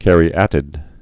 (kărē-ătĭd)